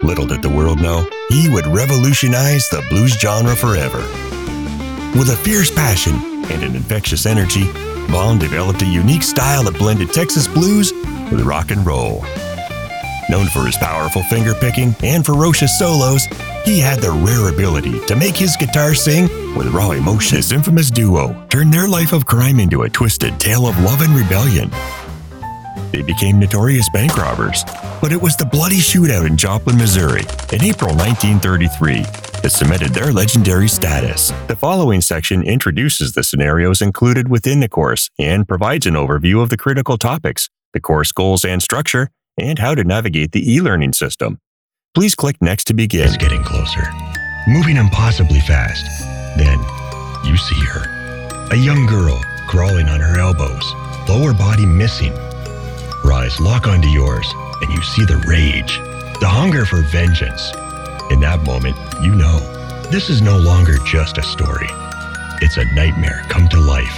Versatile male voice ready to bring goodness to your project!
Canadian General, American General
I record from my professionally-equipped and sound-treated home studio using gear from Focusrite, Rode, Adobe, Waves Audio, Dell and Shure.
Commercial